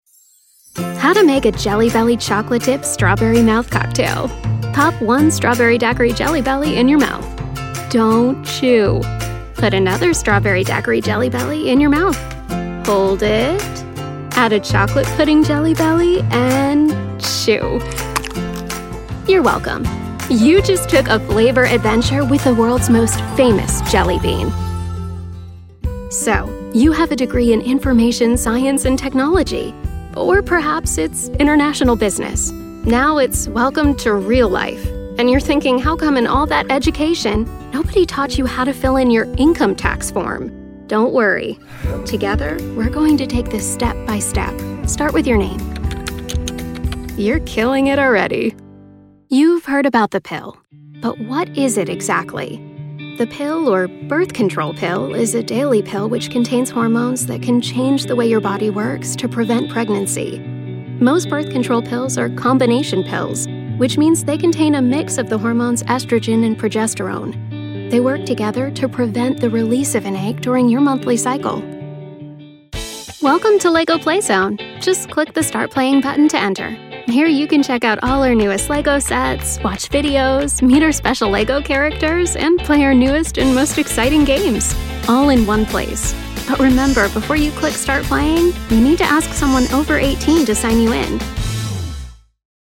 Female
American English (Native)
Bright, Bubbly, Confident, Cool, Engaging, Friendly, Natural, Warm, Versatile, Young
The Girl Next Door, warm, friendly and conversational voice you know and trust.
E-Learning.mp3
Microphone: TLM 103 and Sennheiser MKH 416